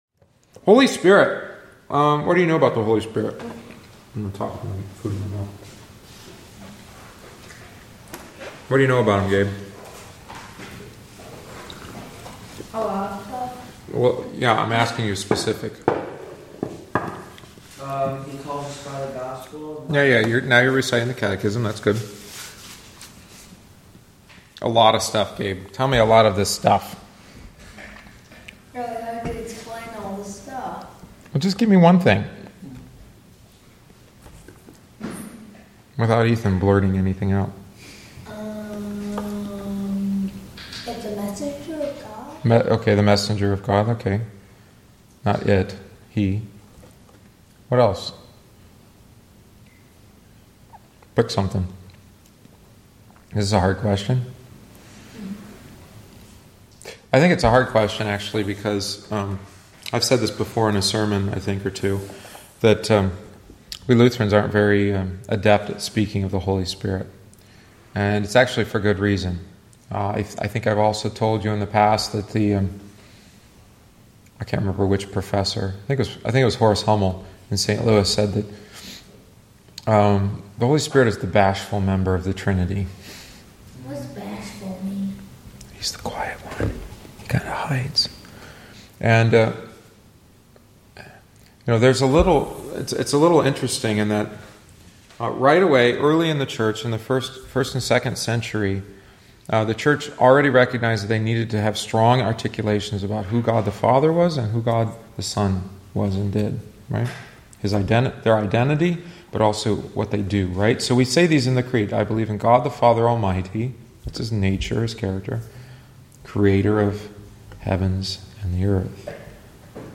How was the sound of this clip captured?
Join us for Adult Catechumenate classes following each Wednesday Divine Service.